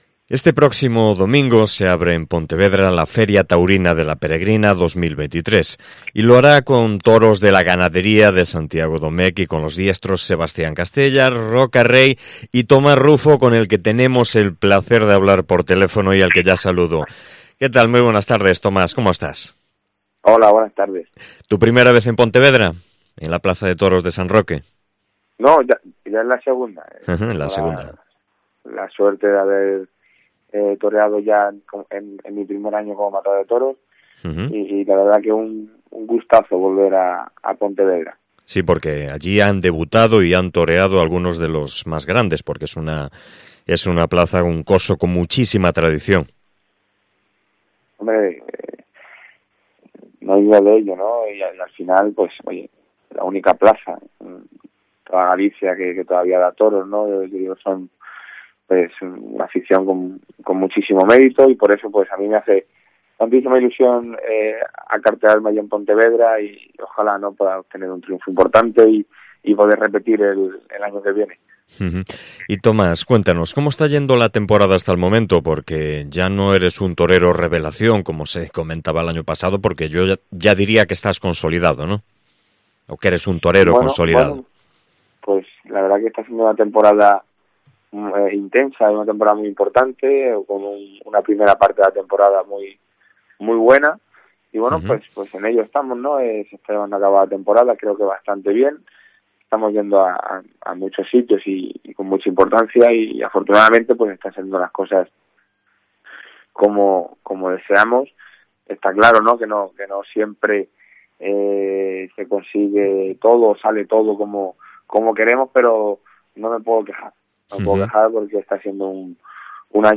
Entrevista con el torero Tomás Rufo